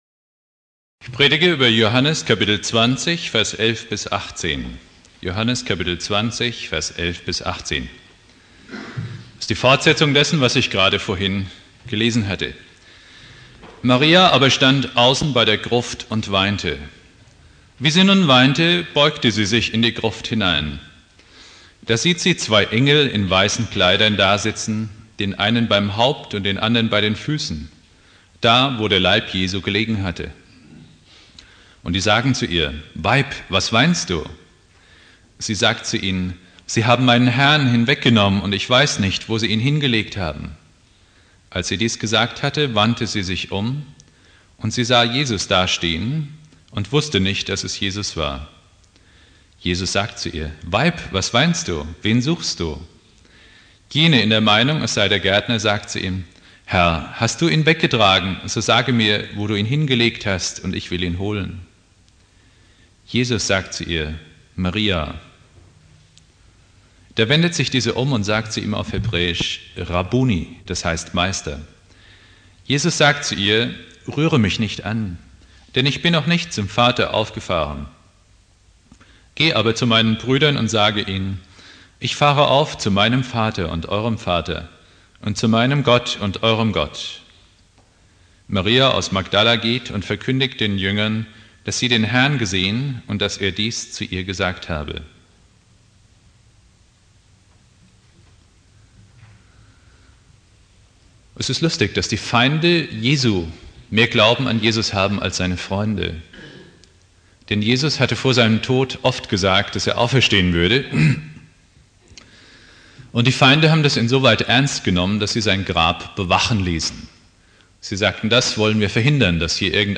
Ostersonntag